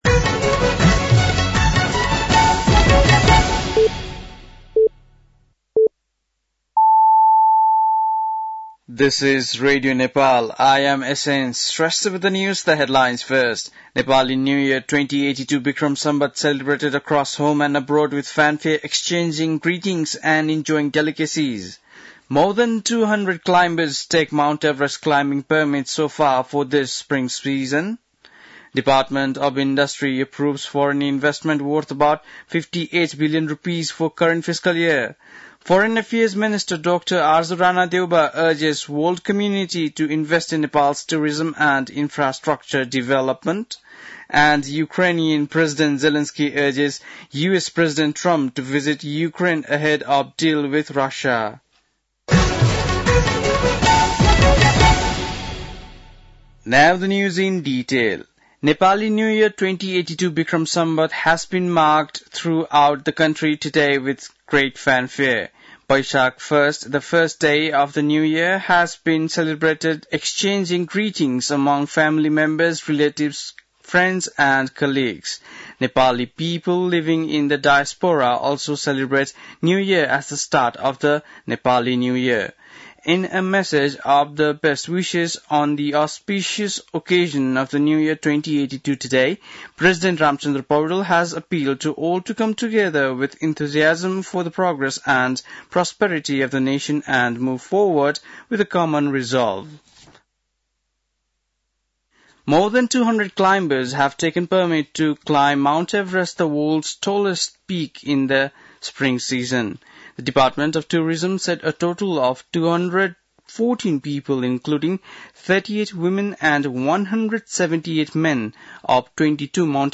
बेलुकी ८ बजेको अङ्ग्रेजी समाचार : १ वैशाख , २०८२
8.-PM-english-News-1-1.mp3